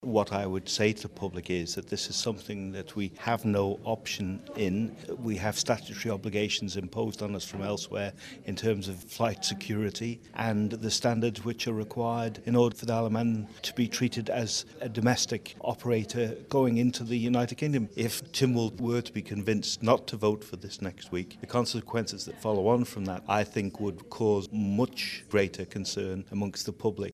Mr Cretney told 3FM that although he understands public concern over the amount of money being spent his department has no choice.